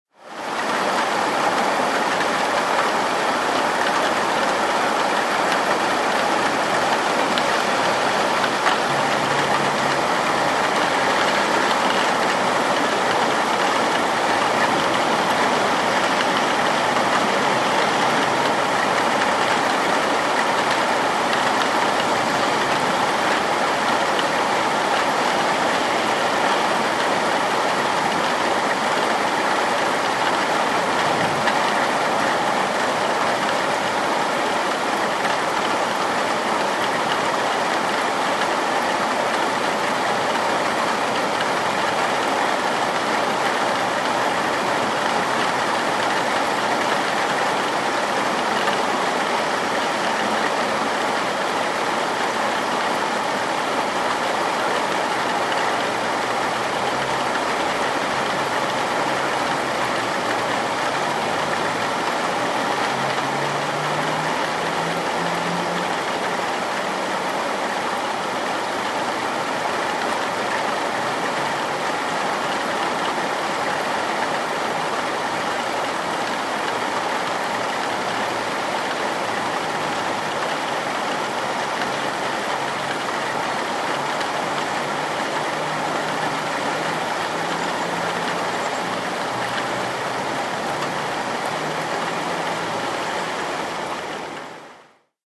Звук ливня с ударами дождя по металлической крыше частного дома